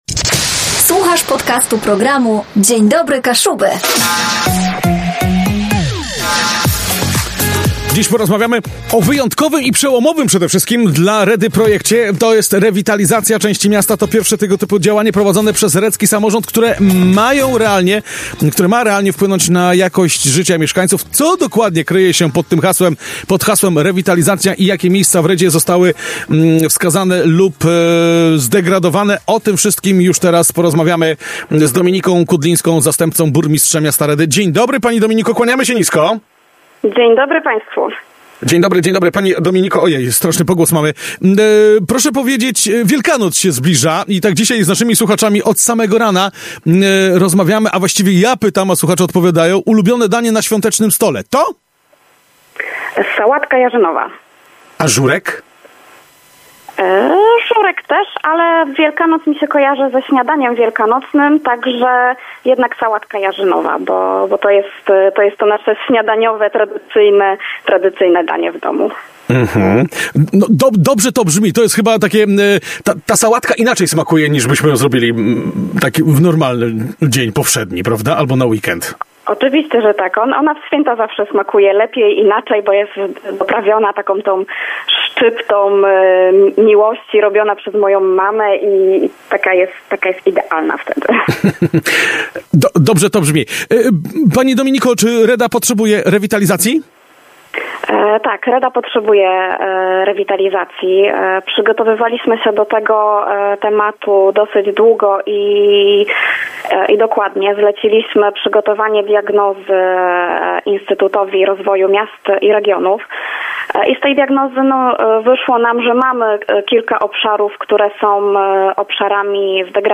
Władze Redy, we współpracy z ekspertami i mieszkańcami, przygotowują gruntowne zmiany, które mają na celu podniesienie jakości życia w zdegradowanych obszarach. Zastępca burmistrza Dominika Kudlińska w rozmowie z Radiem Kaszëbë zdradza szczegóły diagnozy, plany na przyszłość i zaangażowanie mieszkańców w ten kluczowy proces.
DDK-rozmowa-Dominika-Kudlinska-zastepca-burmistrza-miasta-Redy.mp3